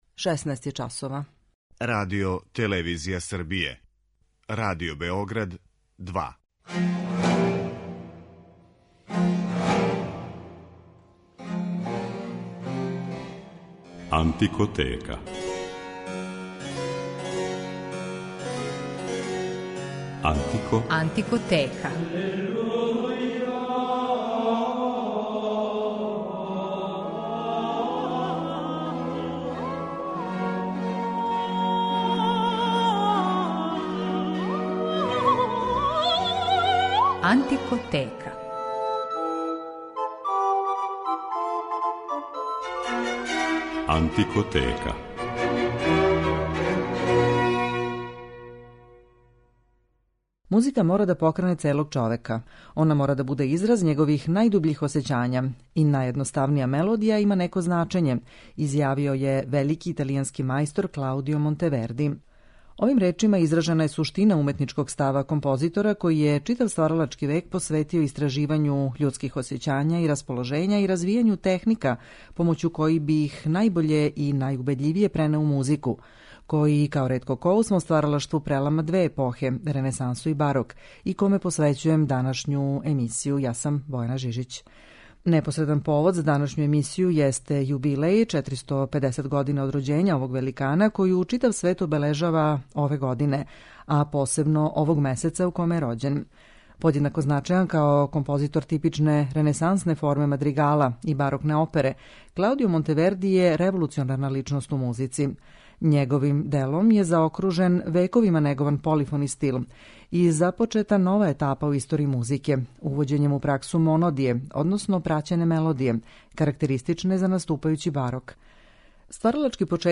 Монтевердијеви мадригали